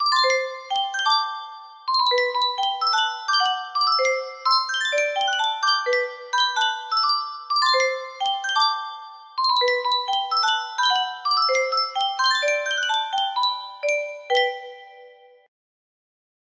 Yunsheng Music Box - Unknown Tune 1726 music box melody
Full range 60